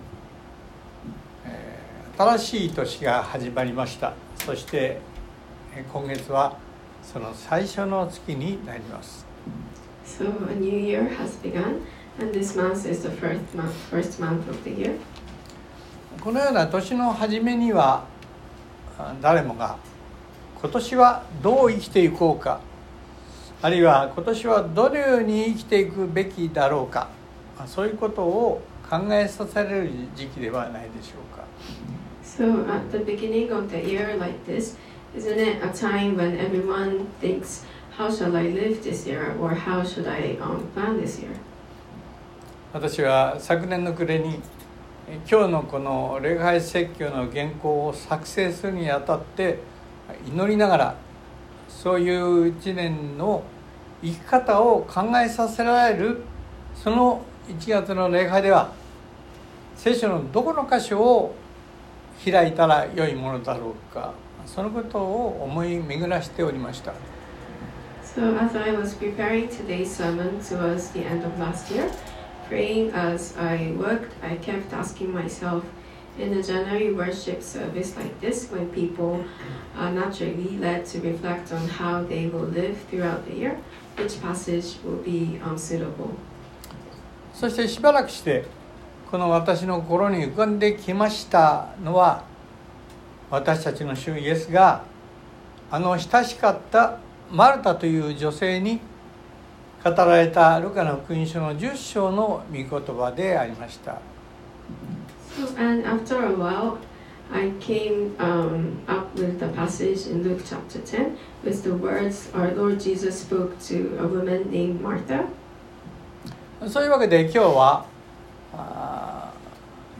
（日曜礼拝録音）【iPhoneで聞けない方はiOSのアップデートをして下さい】①新しい年がはじまりました。